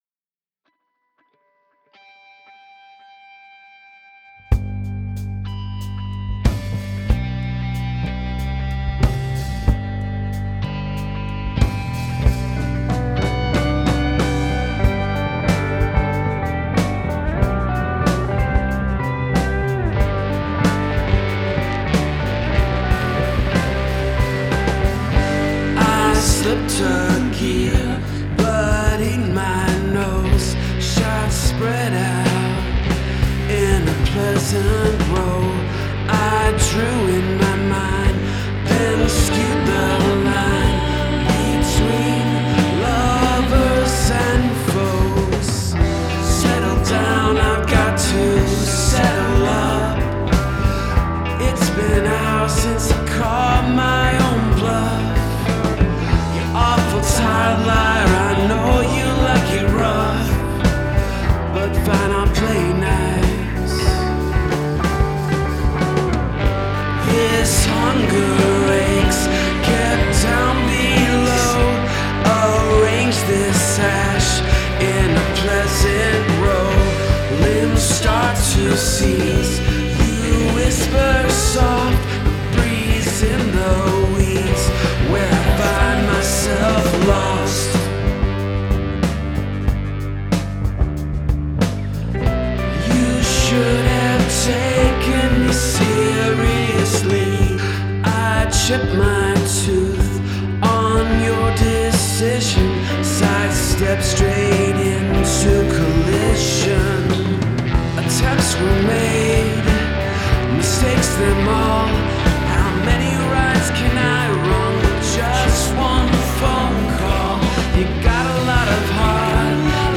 Must include a guest singing or speaking in another language
guest vocals